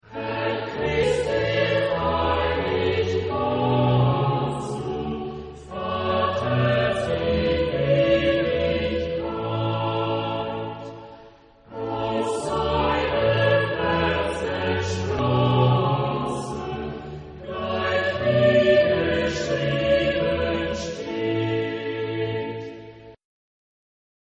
Genre-Stil-Form: Barock ; geistlich ; Choral
Chorgattung: SATB  (4 gemischter Chor Stimmen )
Tonart(en): B-dur